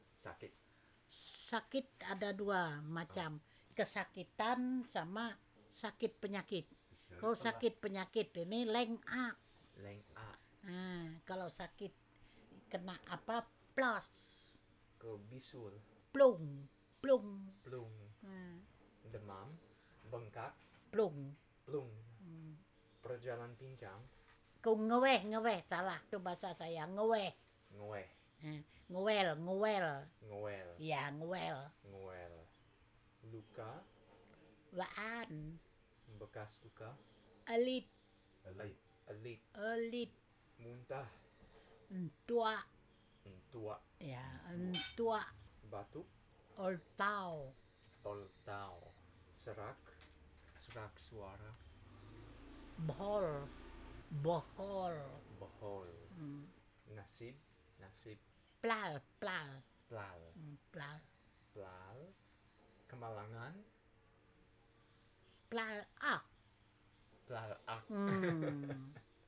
digital wav file recorded at 44.1 kHz/16 bit on a Zoom H6 recorder
East Kutai Regency, East Kalimantan, Indonesia; recording made in Samarinda, East Kalimantan, Indonesia